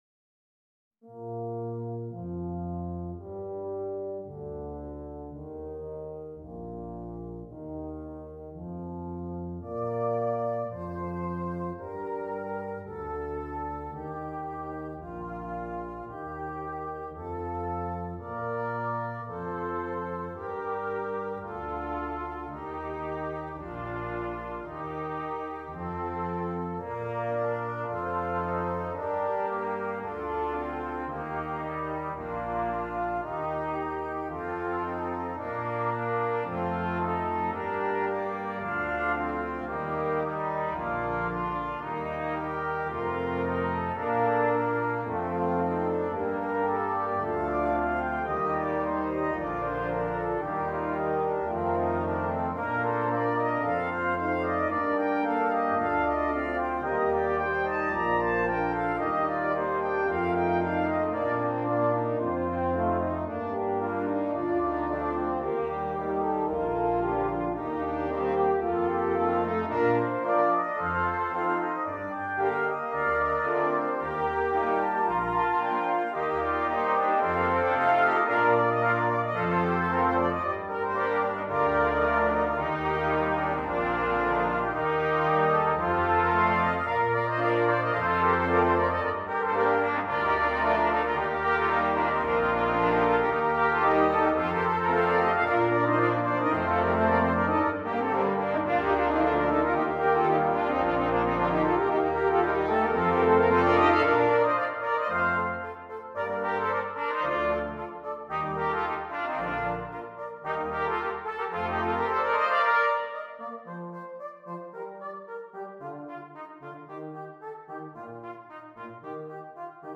Brass Choir (4.4.3.1.1)